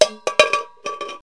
bigbolt.mp3